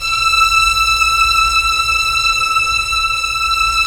Index of /90_sSampleCDs/Roland LCDP13 String Sections/STR_Violins I/STR_Vls1 Sym wh%